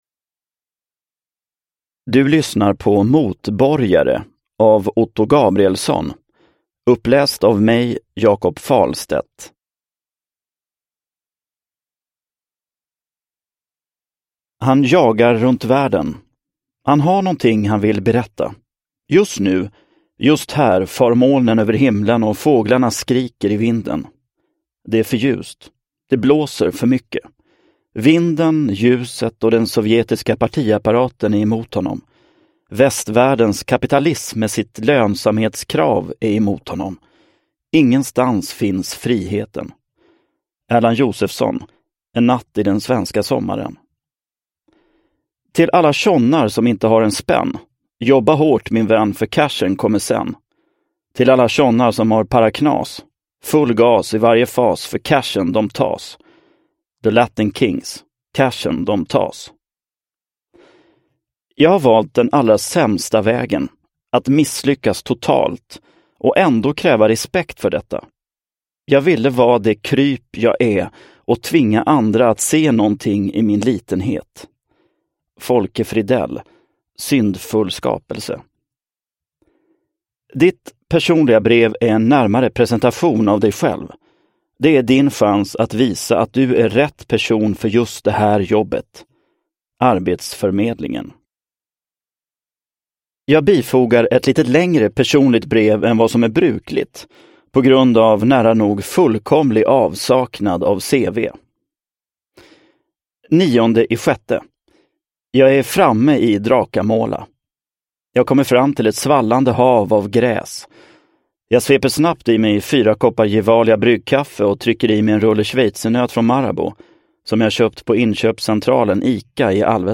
Motborgare – Ljudbok – Laddas ner